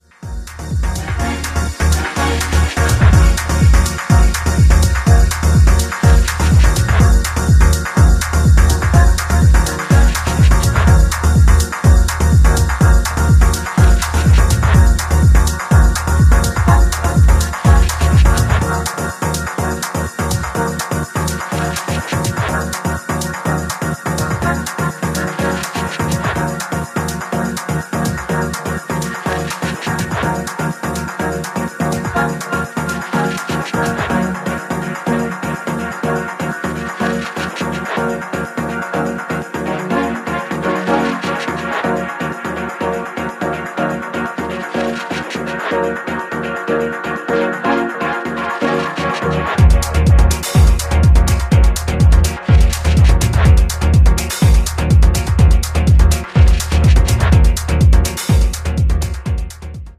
グルーヴィーでフロア映えするテック・チューンのA面、ディープで浮遊感溢れるB面共に派手さ控えめの渋い仕上がり！